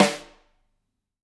Index of /musicradar/Kit 2 - Acoustic room
CYCdh_K2room_Rim-04.wav